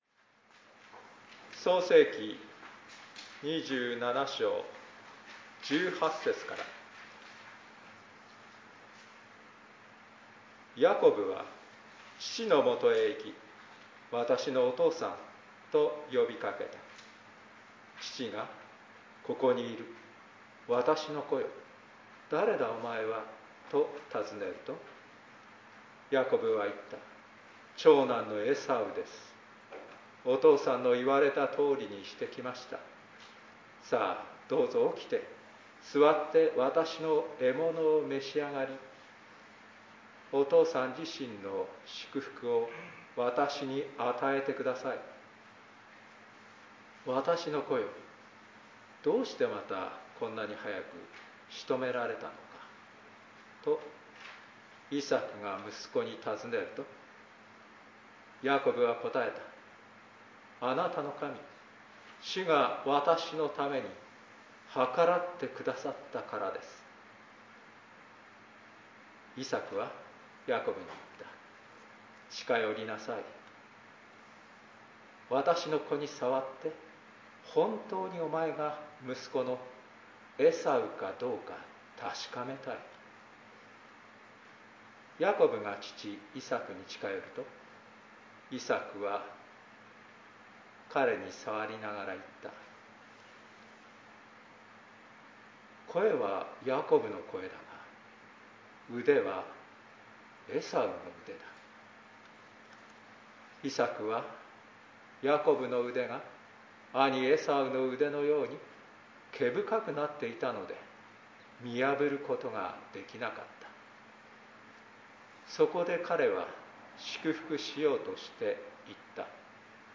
祝福を受け継ぐ（聖霊降臨日第5主日 2022.7.3 説教録音など）